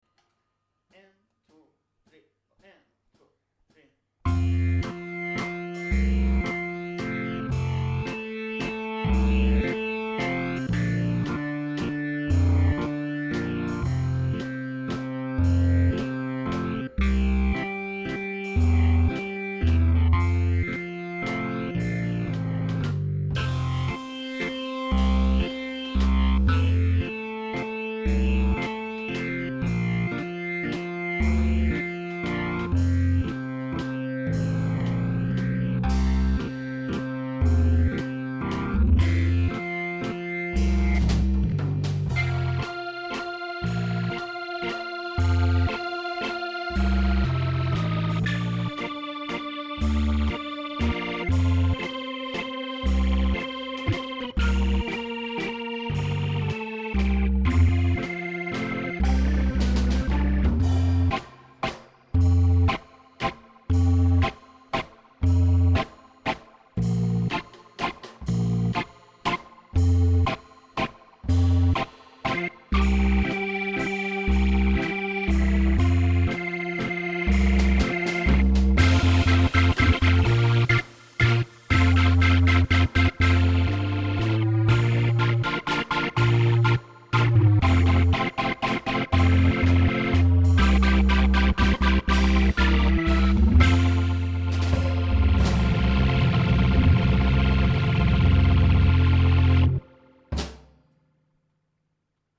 08 Vals.mp3